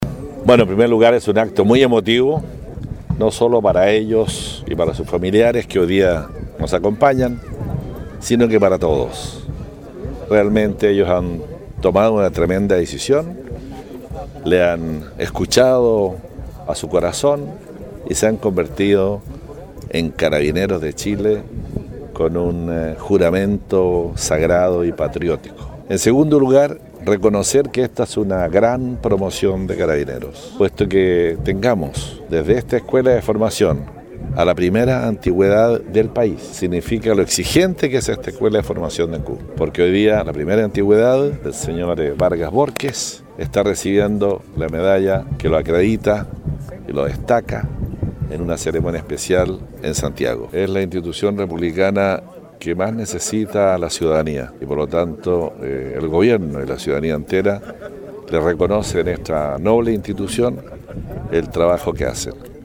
La escuela de formación policial de Ancud realizó la ceremonia de graduación de 145 nuevos Carabineros en la jornada del domingo 01 de diciembre.
Fue precisamente el jefe regional quien resaltó la importancia de sumar más personal a la institución, la que cuenta con el irrestricto apoyo del gobierno, dijo Harry Jurgensen.